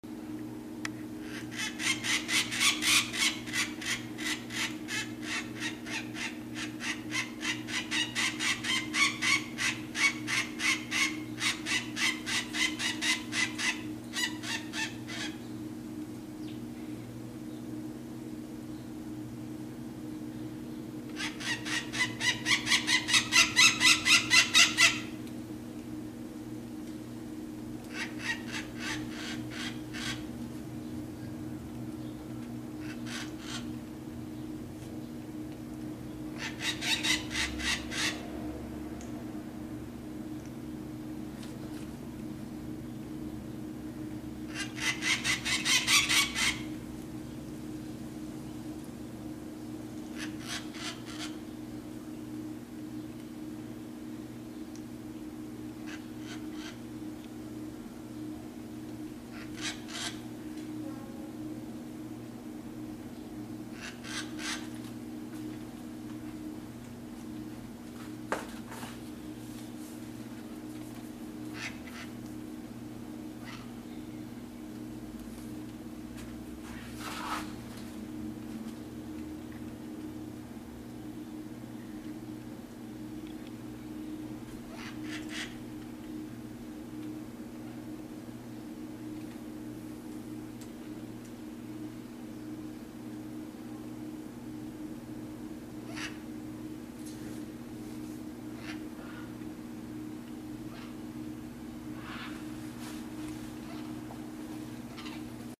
Embora as araras de colarinho amarelo geralmente não sejam tão barulhentas quanto as araras maiores, elas são mais do que capazes de serem bastante barulhentas quando o clima se instala.
Algumas pessoas até comparam seu chamado a uma gaivota, isso significa que elas não podem viver bem es apartamentos ou condomínios.
Canto da arara de colarinho amarelo
canto-da-arara-de-colarinho-amarelo.mp3